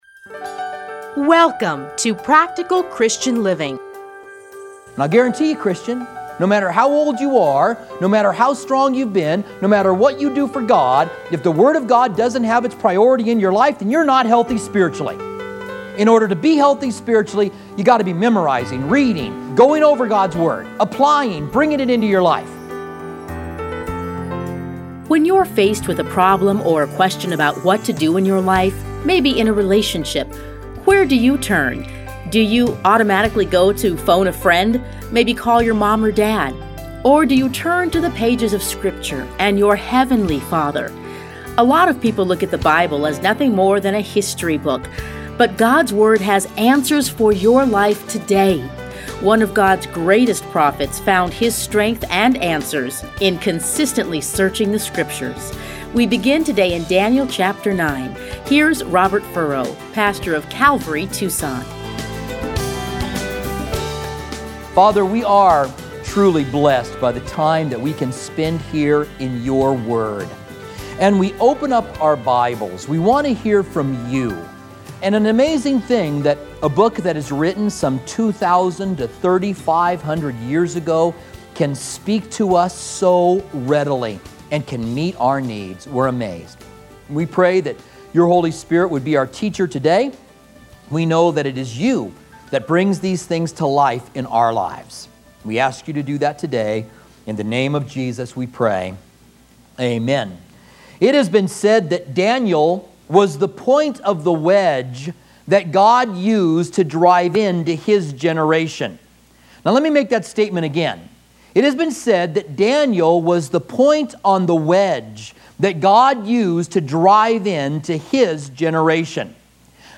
teachings are edited into 30-minute radio programs titled Practical Christian Living